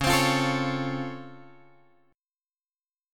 C# Augmented Major 7th